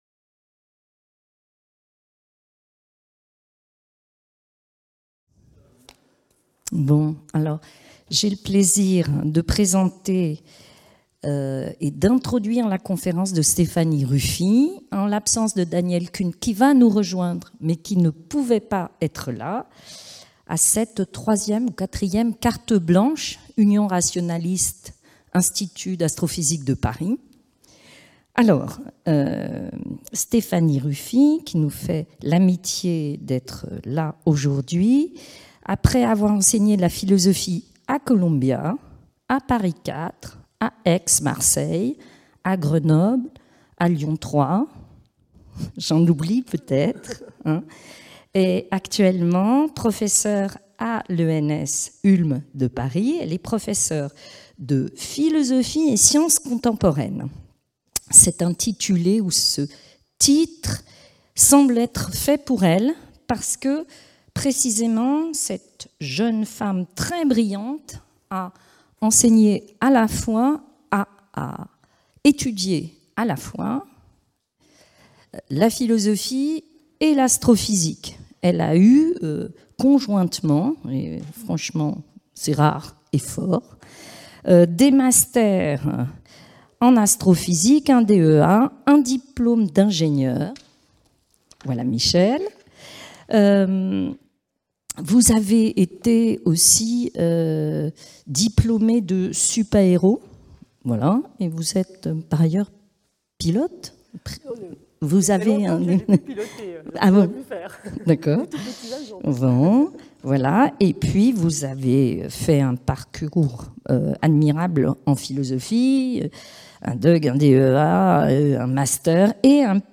Mon hypothèse de travail dans cette conférence sera que les leviers d'action traditionnellement identifiés, comme le développement de la culture scientifique, sont aujourd'hui insuffisants